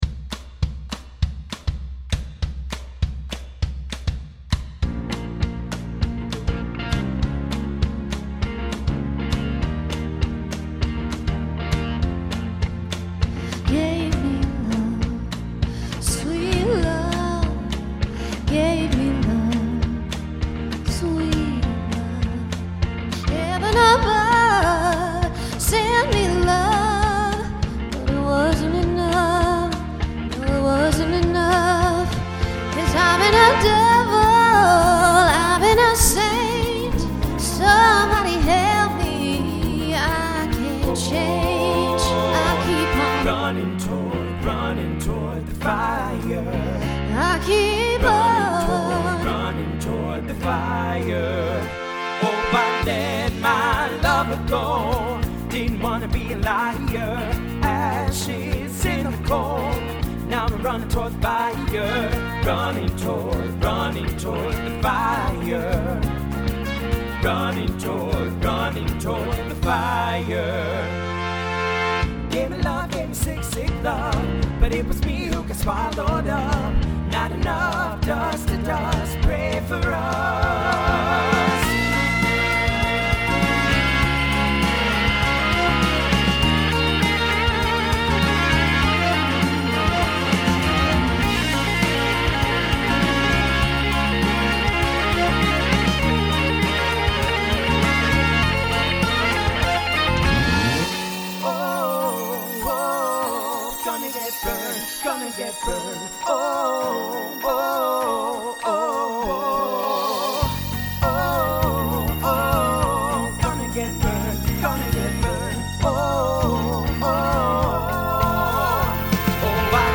Genre Folk , Rock
Voicing TTB